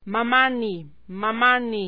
Table I: Sonorised Official Alphabet
Palatal   Velar Postvelar
Nasals m